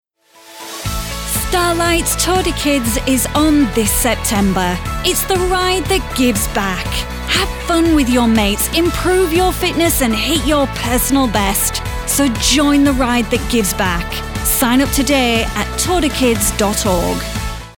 British VO Pro
Tour de kids, charity radio advert